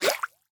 Minecraft Version Minecraft Version latest Latest Release | Latest Snapshot latest / assets / minecraft / sounds / mob / axolotl / attack2.ogg Compare With Compare With Latest Release | Latest Snapshot